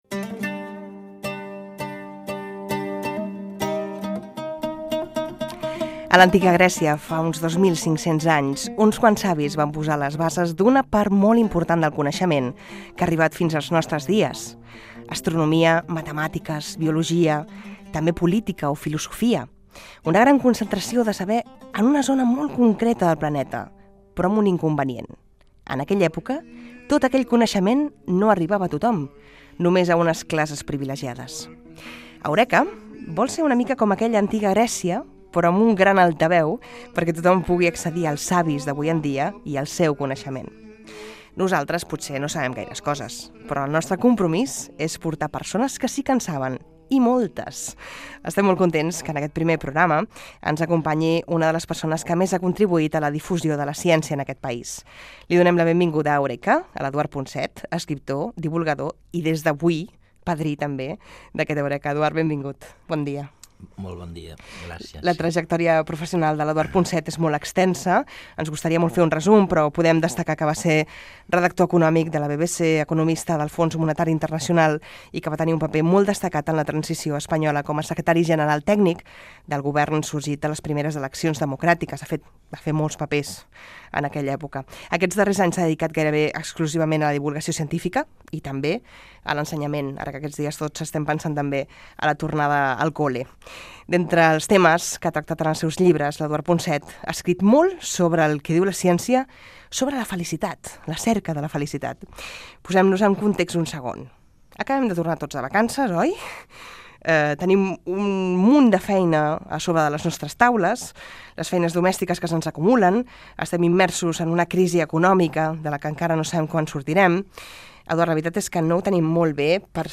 Inici del primer programa. El saber dels grecs i objectiu del programa. Fragment d'una entrevista al divulgador científic Eduard Punset
Divulgació